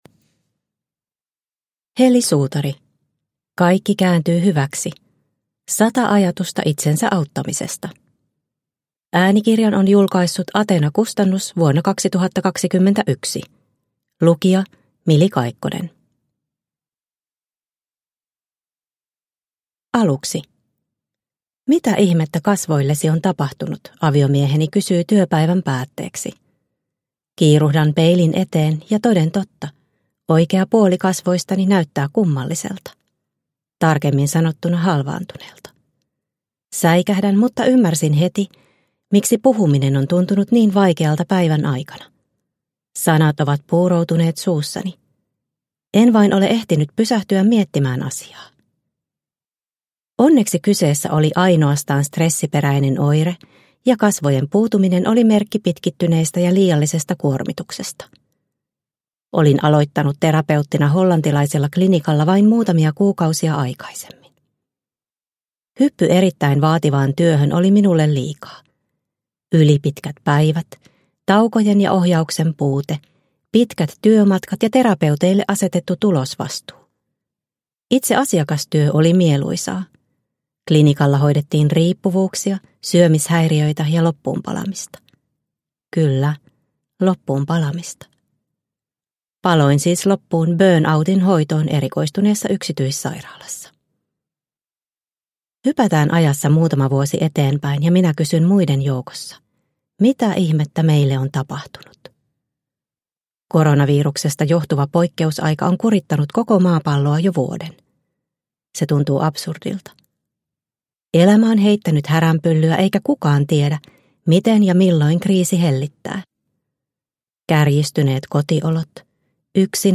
Kaikki kääntyy hyväksi – Ljudbok – Laddas ner